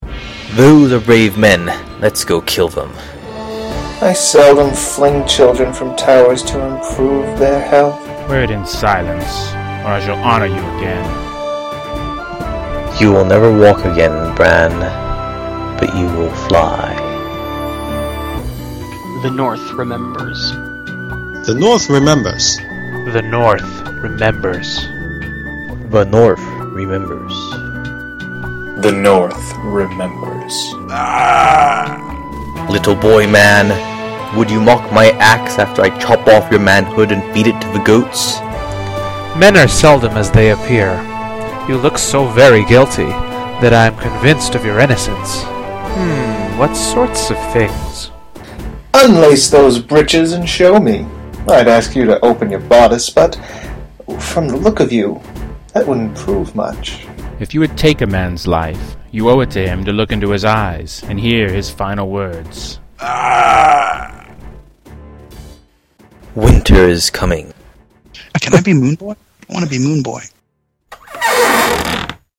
I especially liked the dramatic readings at the beginning and was hoping for an epic bit somewhere in the episode.